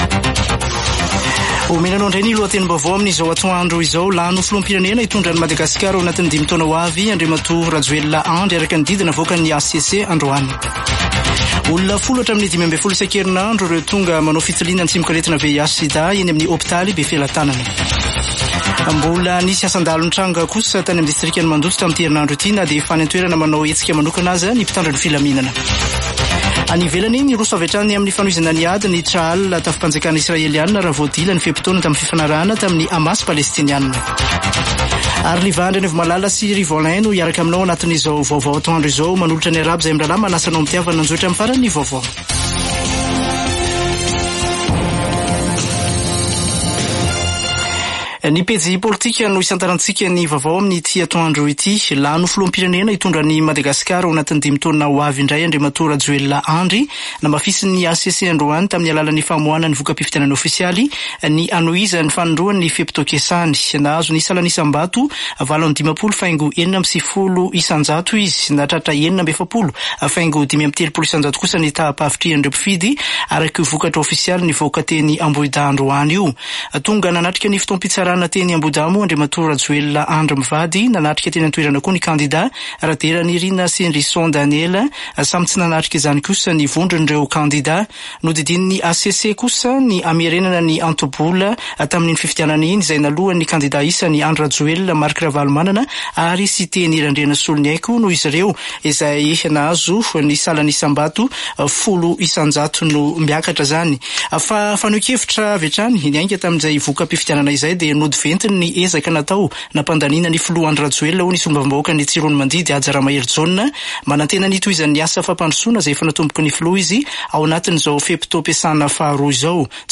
[Vaovao antoandro] Zoma 1 desambra 2023